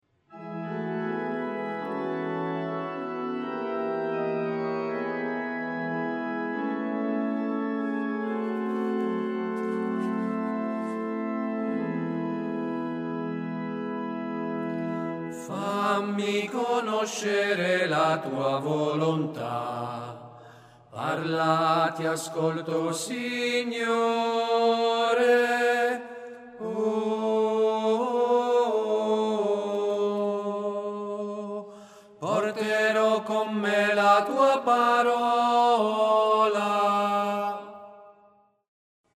03tenori.MP3